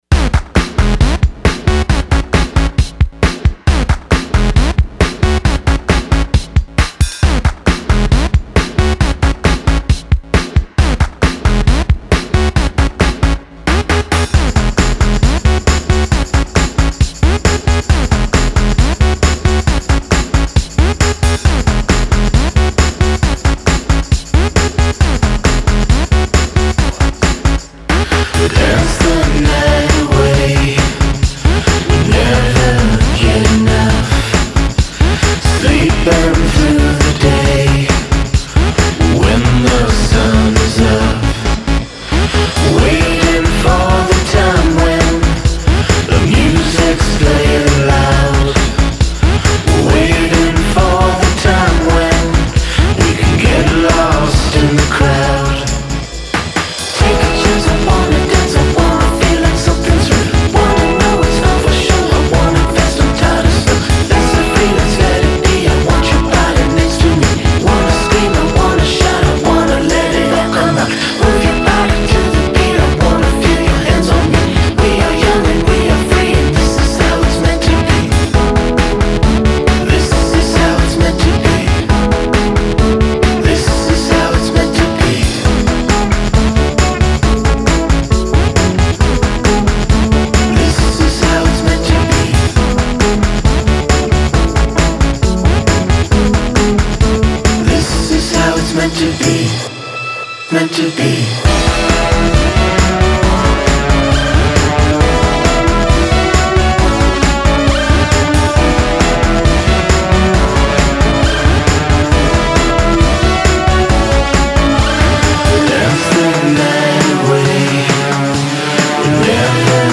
Damn you, catchy pop song, I can’t resist posting you!
I could dance to this, I like it!